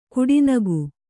♪ kuḍinagu